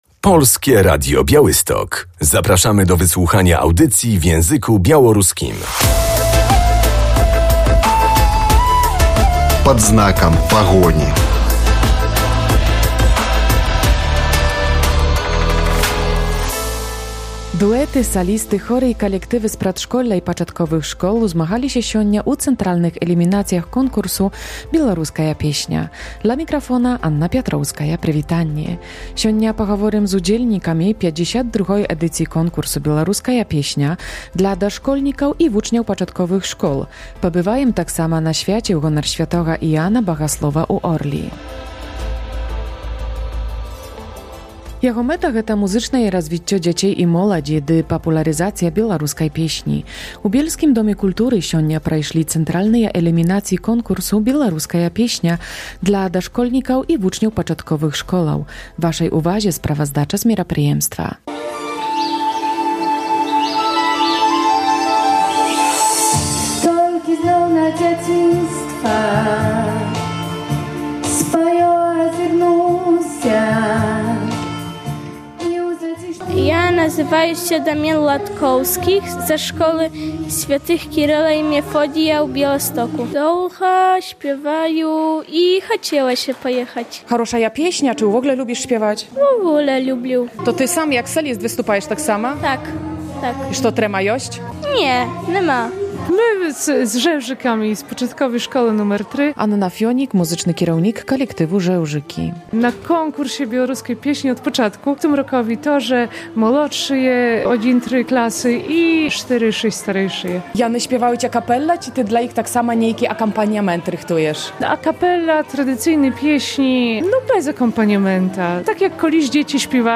Jego celem jest rozwijanie muzycznych zainteresowań dzieci i młodzieży a także popularyzacja piosenki białoruskiej. Soliści, duety ale też zespoły i chóry prezentowały się na scenie Domu Kultury w Bielsku Podlaskim.